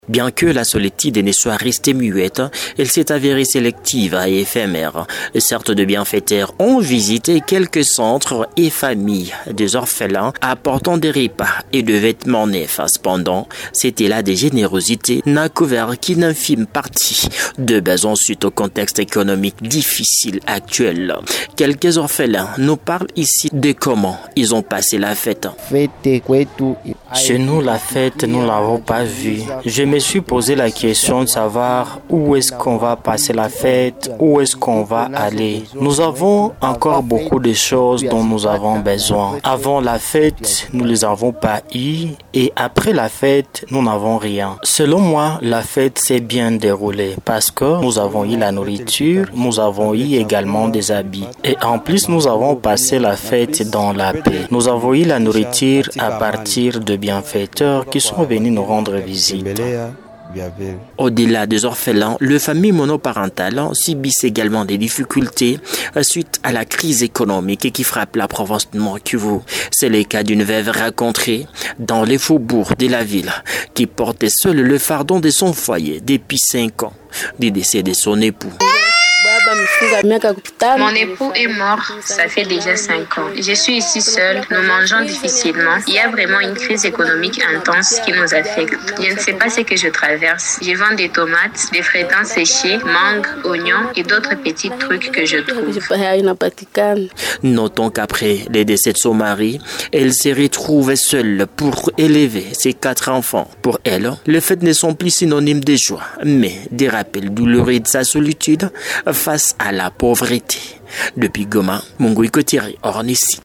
FRANCAIS-REPORTAGE-SUR-ORPHELINS.mp3